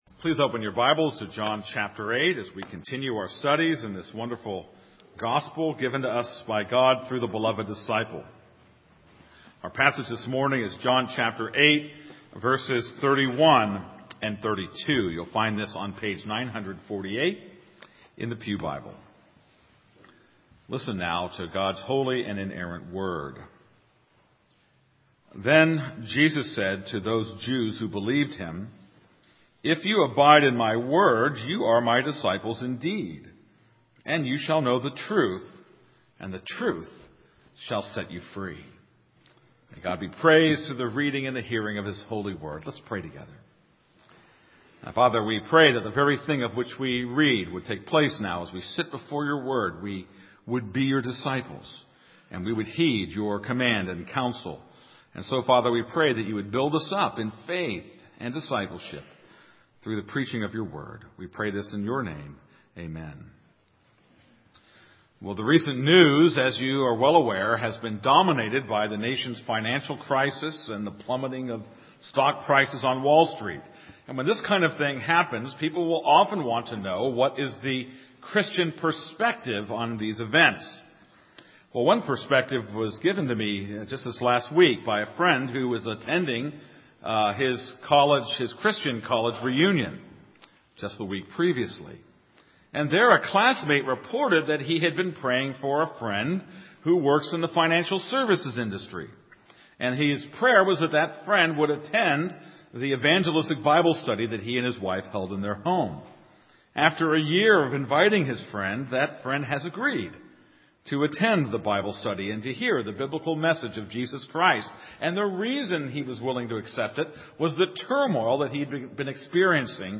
This is a sermon on John 8:31-32.